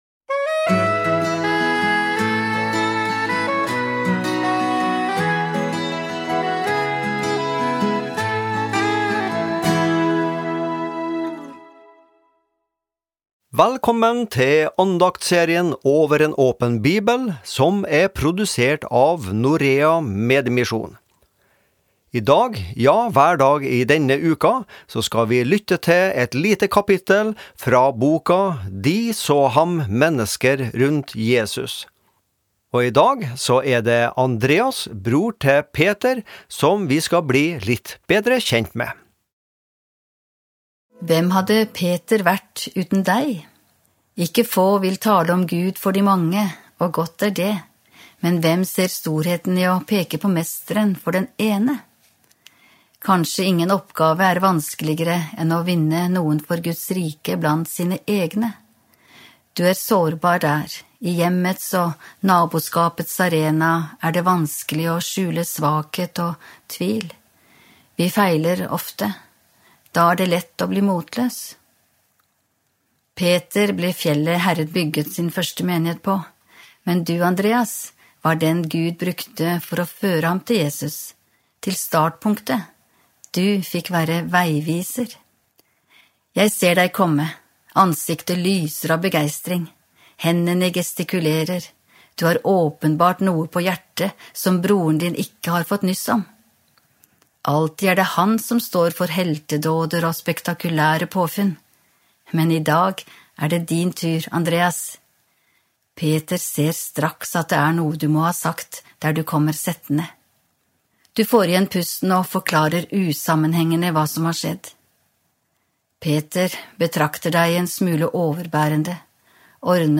Daglige andakter (mand.-fred.). Forskjellige andaktsholdere har en uke hver, der tema kan variere.